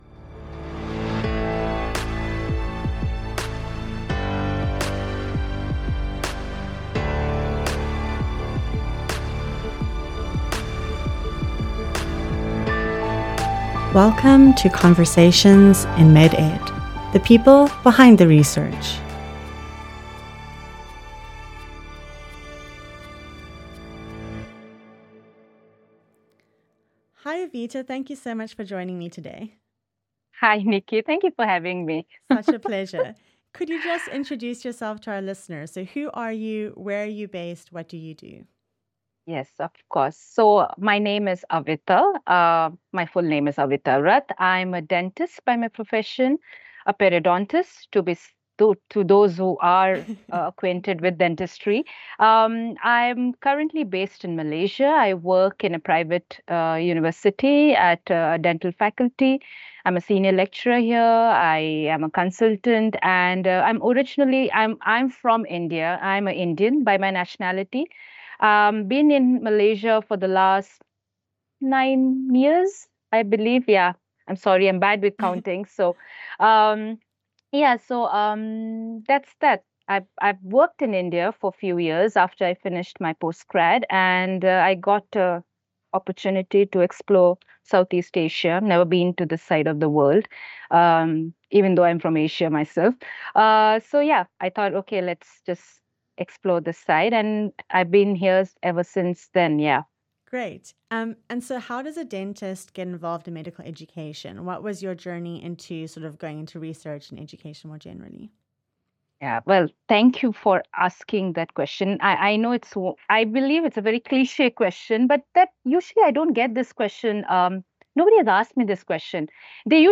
Chatting